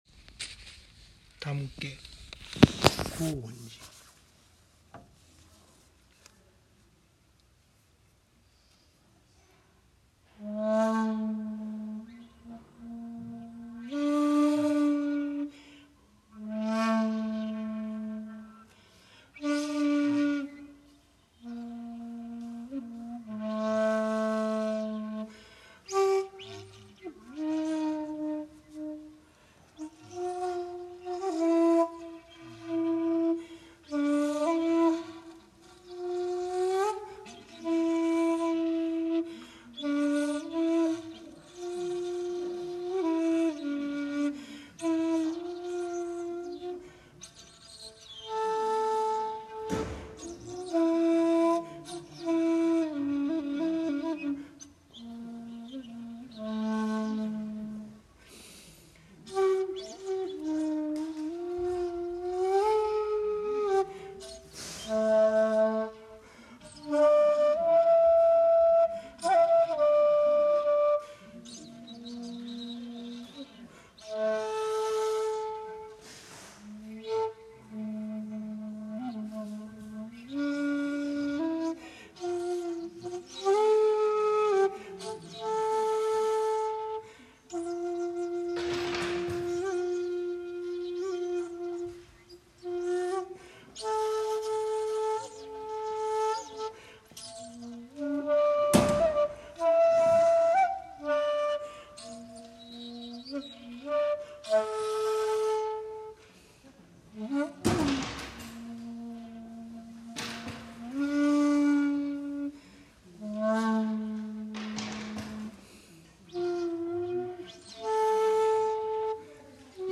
そして本尊の大日如来の前で尺八を吹奏することができました。
（尺八音源：香園寺本堂にて「手向」）